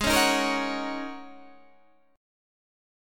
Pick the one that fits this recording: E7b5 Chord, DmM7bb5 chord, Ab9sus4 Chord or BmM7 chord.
Ab9sus4 Chord